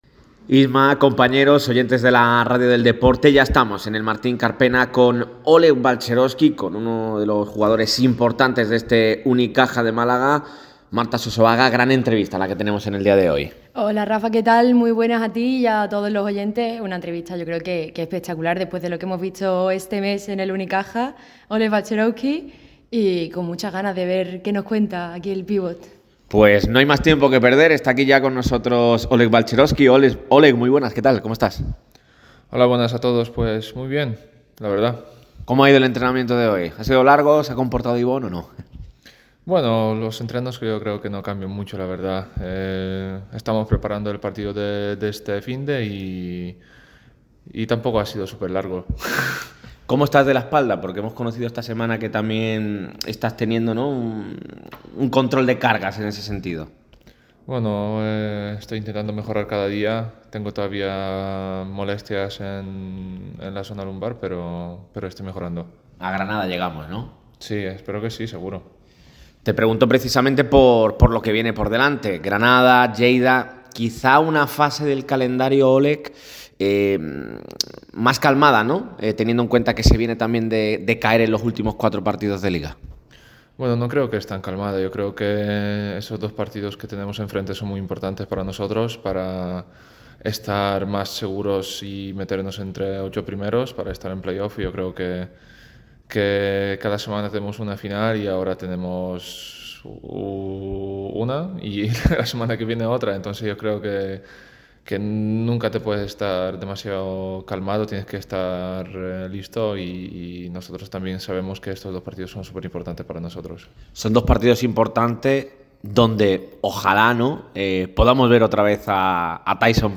Olek Balcerowski atendió en exclusiva a Radio MARCA Málaga después de la clasificación a la Final Four de la BCL y en la previa del derbi andaluz ante Covirán Granada.
Olek Balcerowski ha sido protagonista este miércoles en los micrófonos de Radio MARCA Málaga.
ENTREVISTA-OLEK-BALCEROWSKI-RADIO-MARCA-MALAGA.mp3